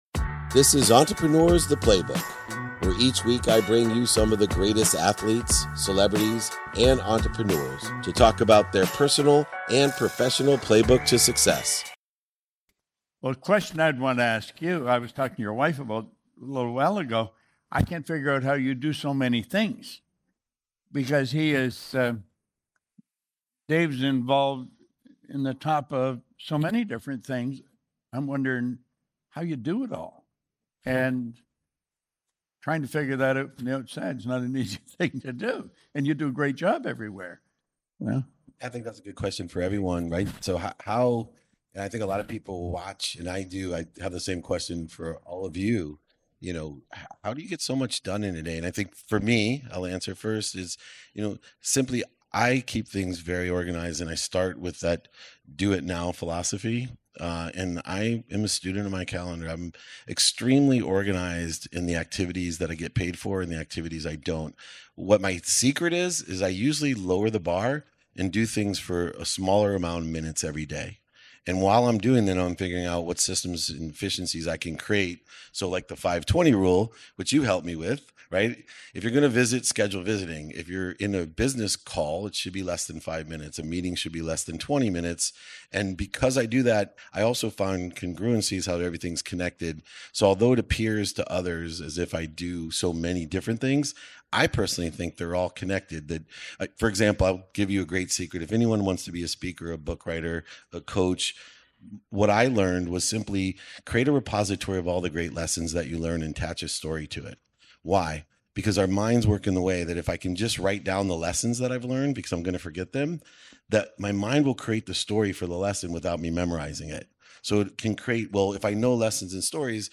Today's episode features a conversation from 2019 with my friend and mentor, the late Bob Proctor. We explore the secrets behind achieving more in less time. The key? It’s not about managing time but managing activities.